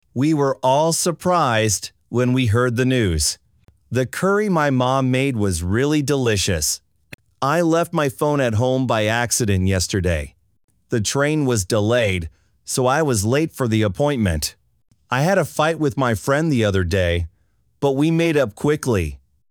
アメリカ人